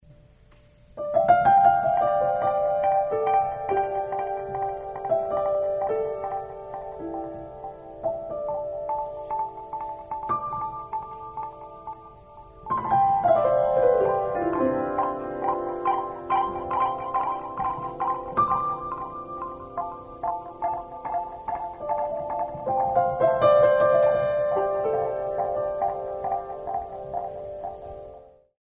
Recorded Live in Japan